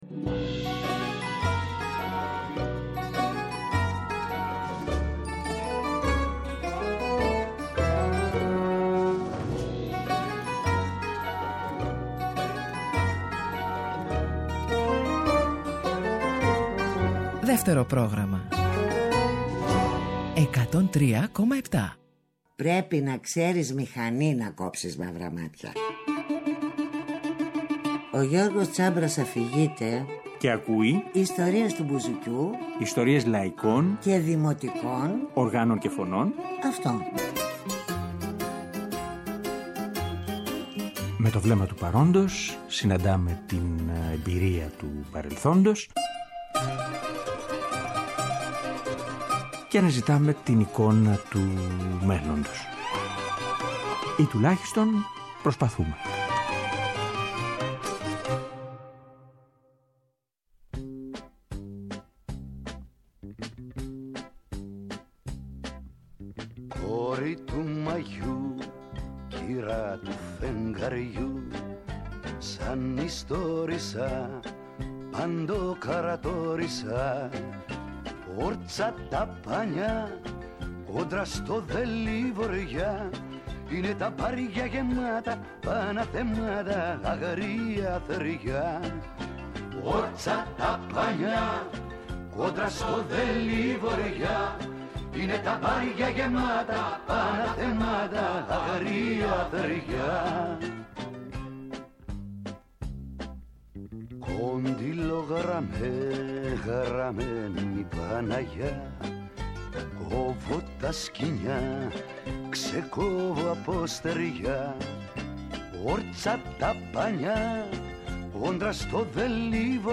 Σκεφτόμουν να ακούσουμε με τη φωνή του και κάποια τραγούδια που ήταν ανάμεσα στις επιλογές της συναυλίας αλλά και άλλα, που έχουμε καιρό να ακούσουμε.